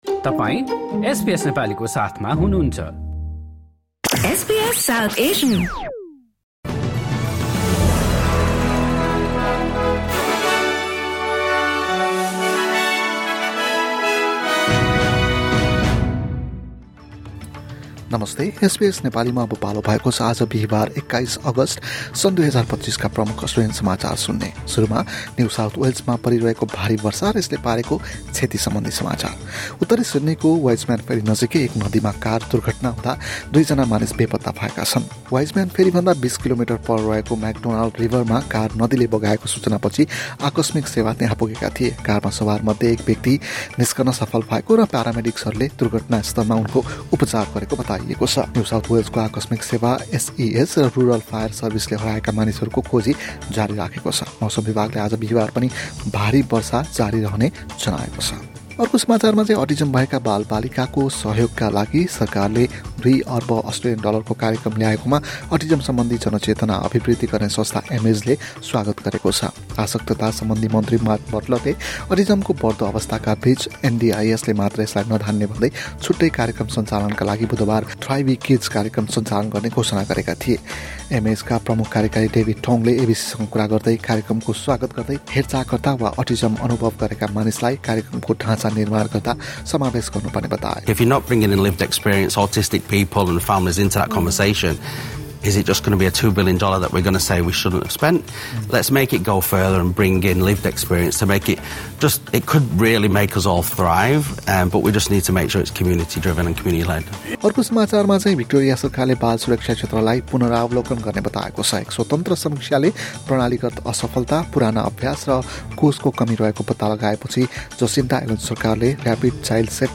एसबीएस नेपाली प्रमुख अस्ट्रेलियन समाचार: बिहीवार, २१ अगस्ट २०२५